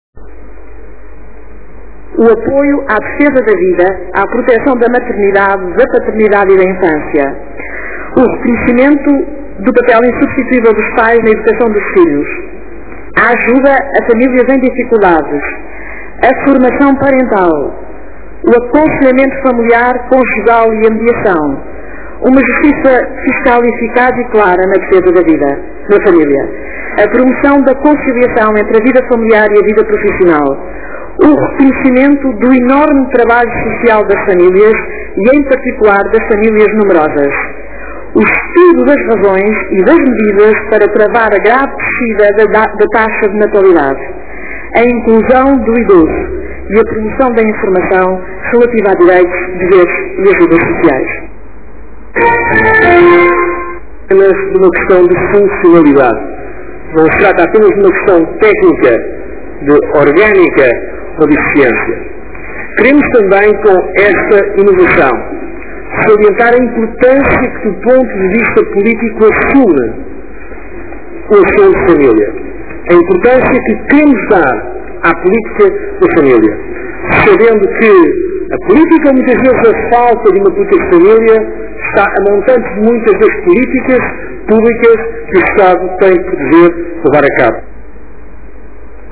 A tomada de posse da coordenadora dos assuntos da família, Margarida Neto, foi hoje realizada no Palácio Foz, com a presença de sete ministros.